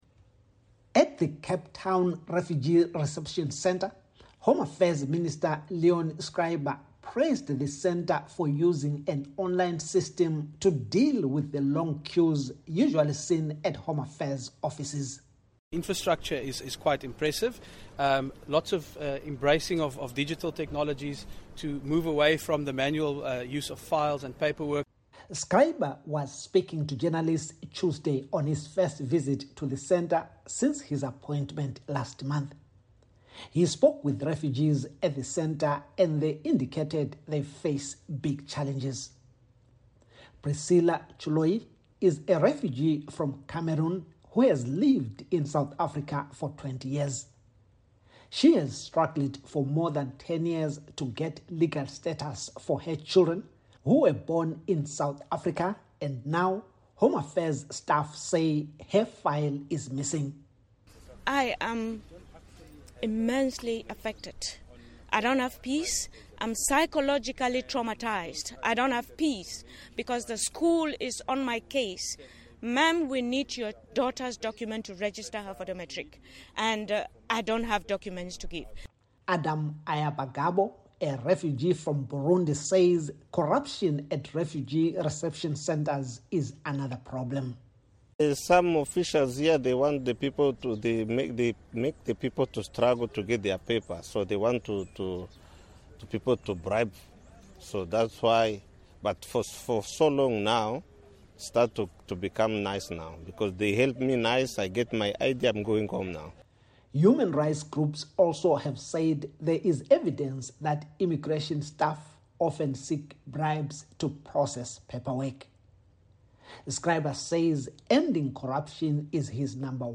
reports from Cape Town.